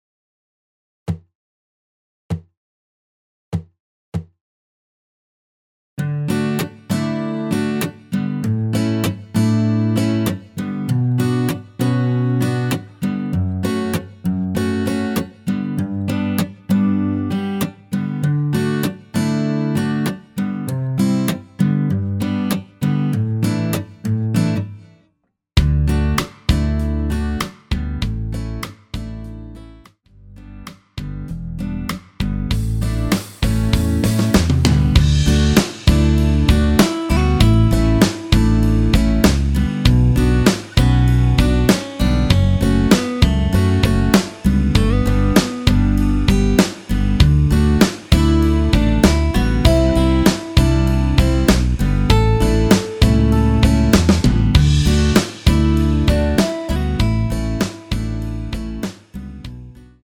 노래가 바로 시작 하는 곡이라 전주 만들어 놓았습니다.
D
앞부분30초, 뒷부분30초씩 편집해서 올려 드리고 있습니다.
중간에 음이 끈어지고 다시 나오는 이유는